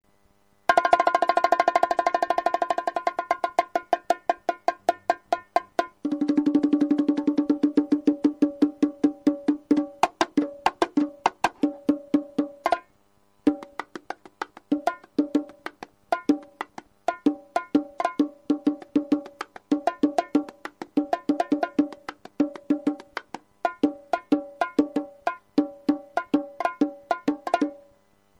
■ウッドボンゴ
ウッドボンゴは、ウッドならではの豊かな倍音による深みのあるサウンドが特徴です。